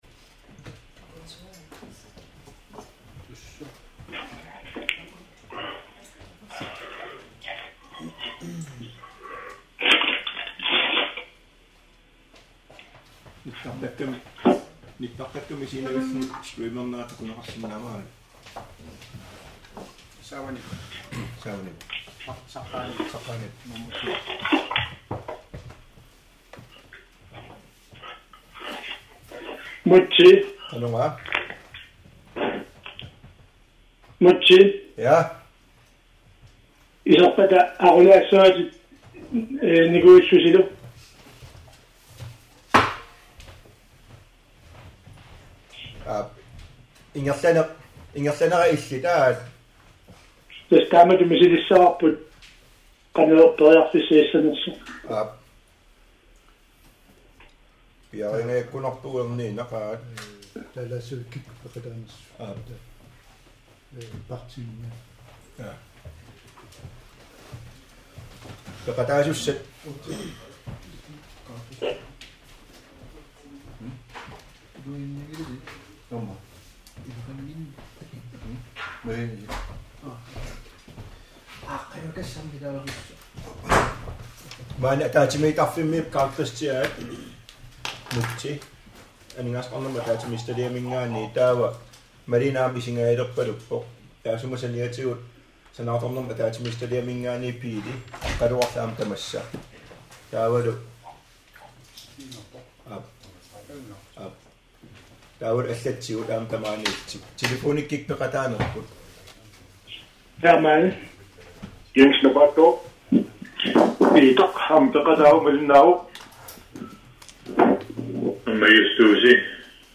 Aningaasaqarnermut Akileraartarnermullu Ataatsimiititaliami siunersiuineq pingasunngornermi ulloq 09. septemberi 2020, nal. 10.00